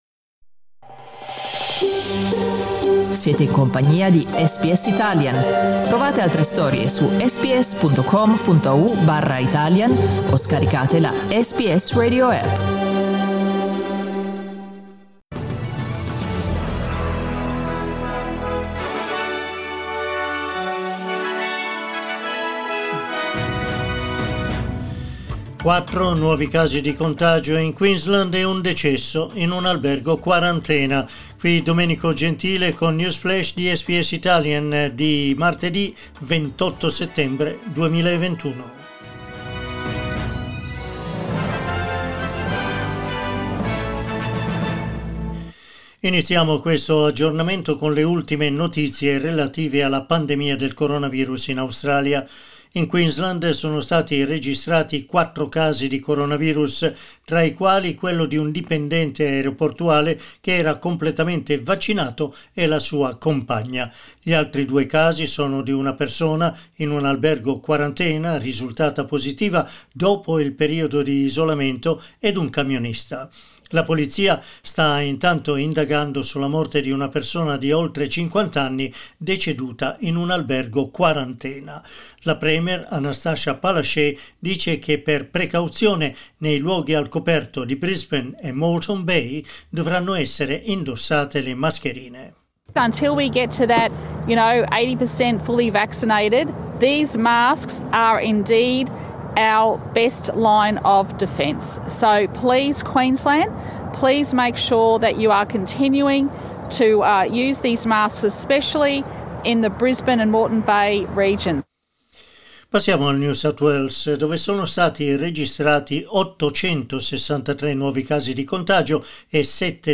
News Flash martedì 28 settembre 2021
L'aggiornamento delle notizie di SBS Italian.